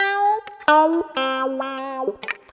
134 GTR 4 -R.wav